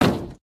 sounds / material / human / step / metall02gr.ogg
metall02gr.ogg